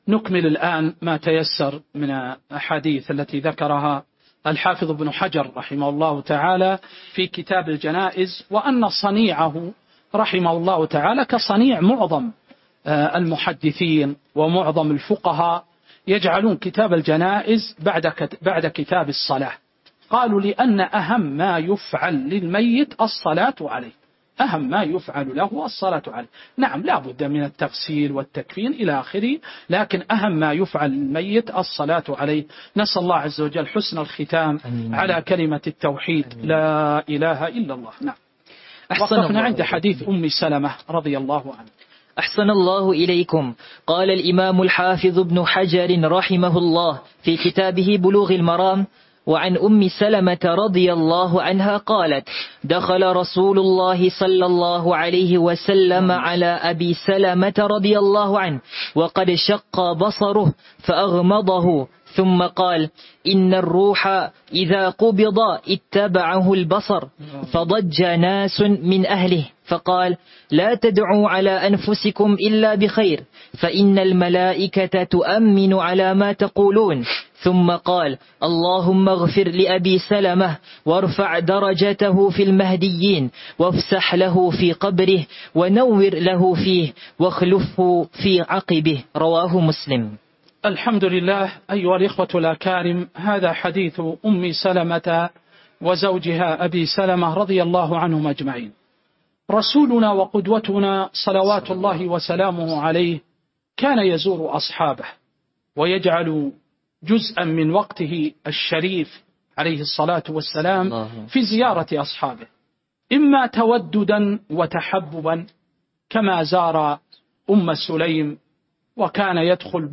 تاريخ النشر ١١ شعبان ١٤٤٥ هـ المكان: المسجد النبوي الشيخ